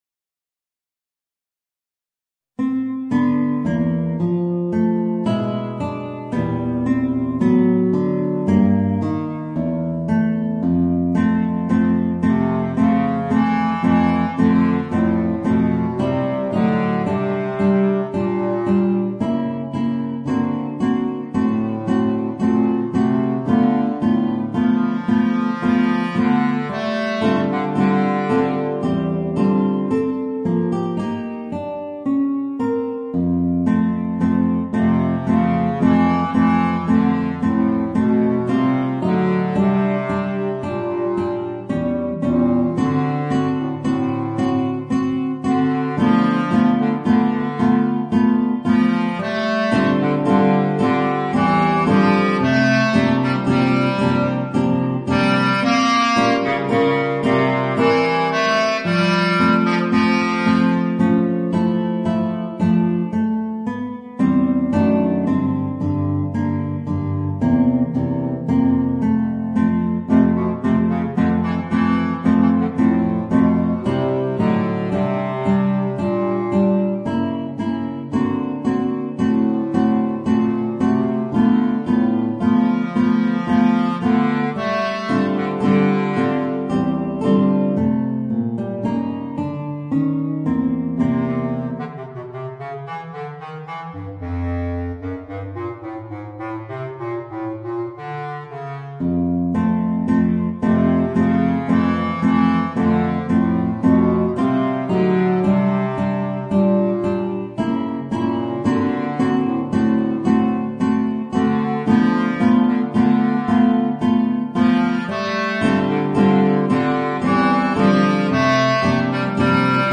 Voicing: Guitar and Bass Clarinet